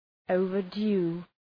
{,əʋvər’du:}